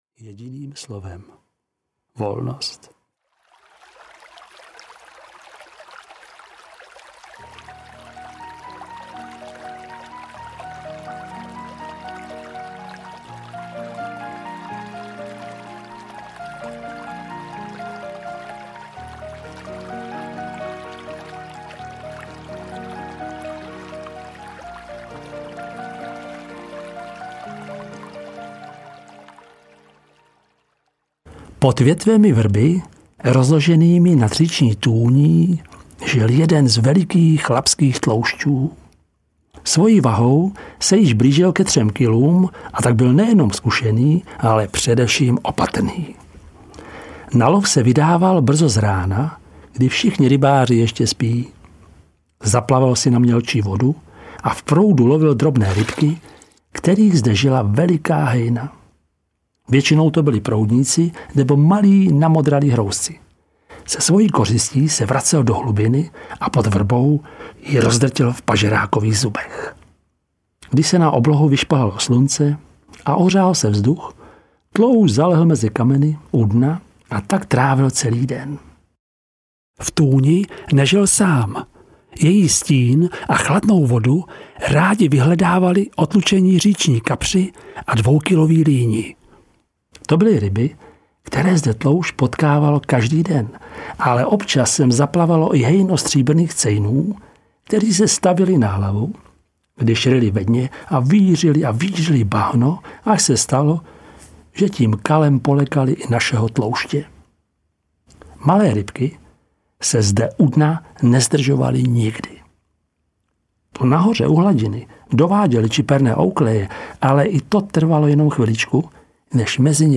Rybí profesor audiokniha
Ukázka z knihy